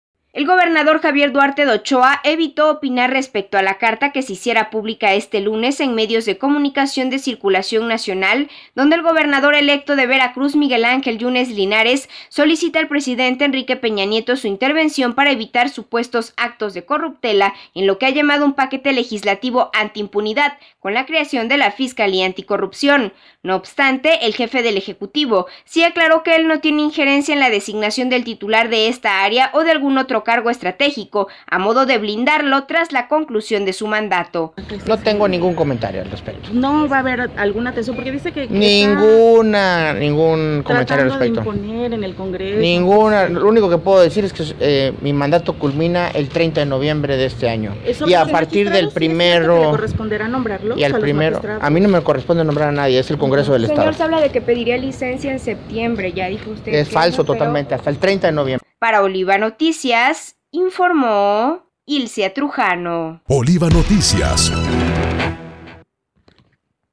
En breve entrevista, previo a su llegada a la Sede del Poder Judicial donde se llevó a cabo el acto de promulgación de la ley para la Autonomía presupuestal de judicatura del estado, Duarte de Ochoa, aseguró, que no solicitará licencia ante el Congreso local para separarse del cargo antes del 30 de noviembre (fecha en que finaliza su gobierno conforme a los tiempos constitucionales), como ha trascendido entre la prensa del estado.
02_ht_javier__duarte_de_ochoa_.mp3